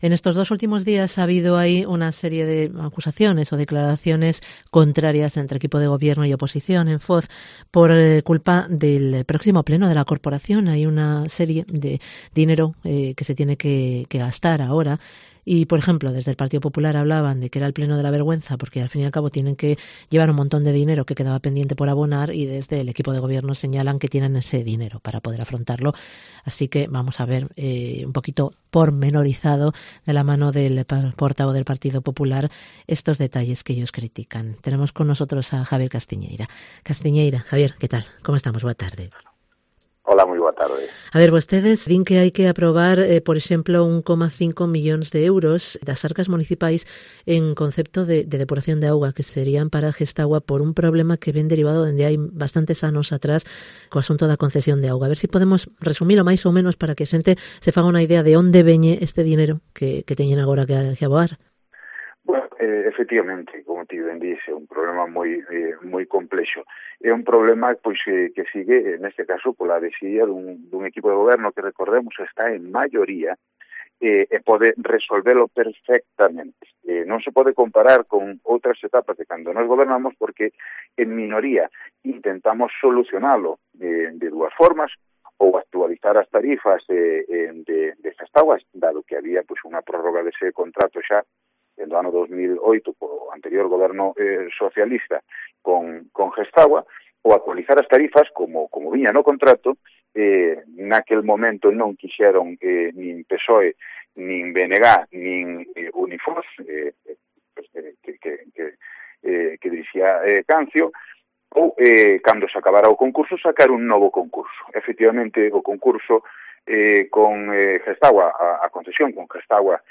Entrevista con Javier Castiñeira, portavoz del PP en Foz